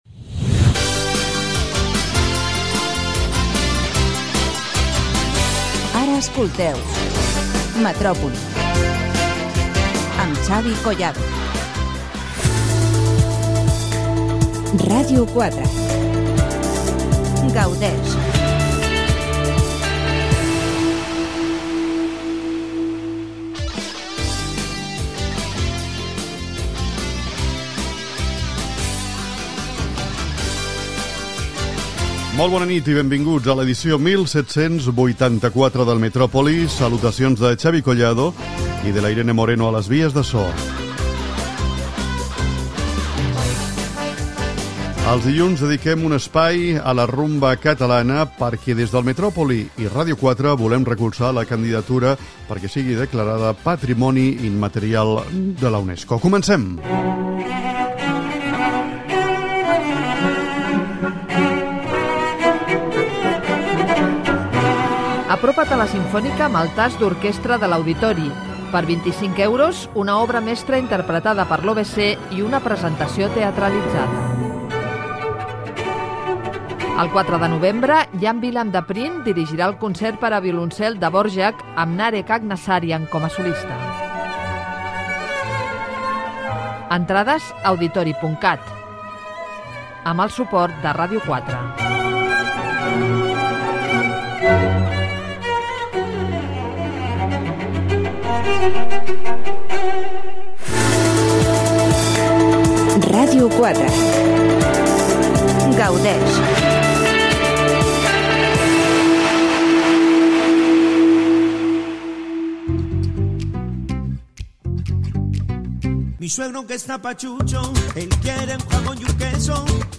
Indicatiu de l'emissora.
Gènere radiofònic Entreteniment